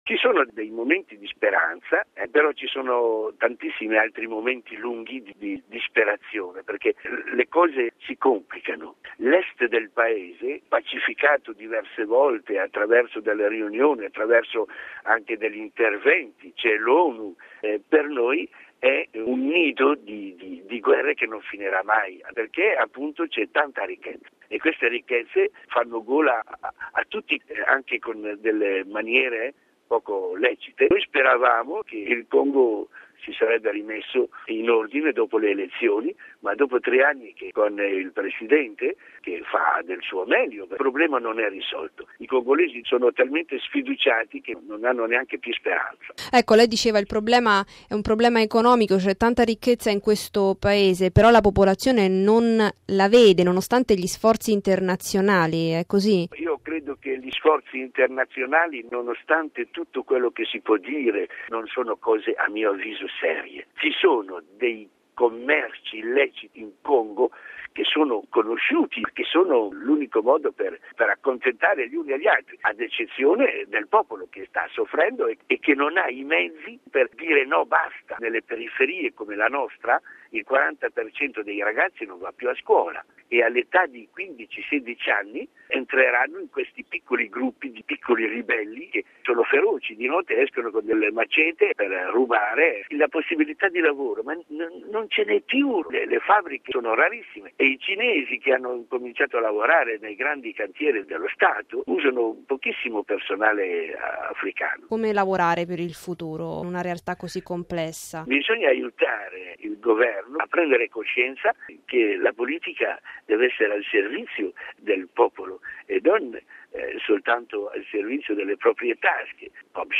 E’ quanto testimonia un padre missionario di Kinshasa che per ragioni di sicurezza ha chiesto di rimanere anonimo.